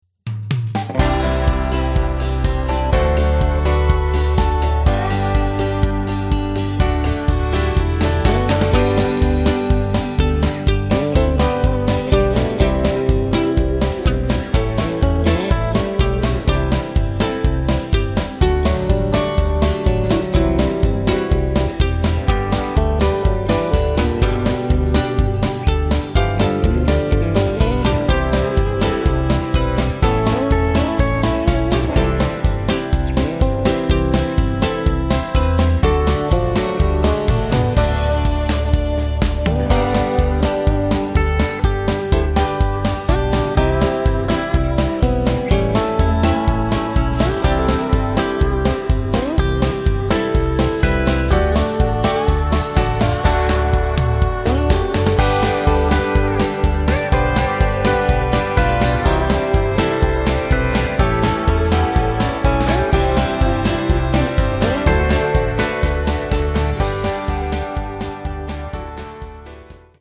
Square Dance Music
Music sample